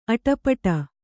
♪ aṭapaṭa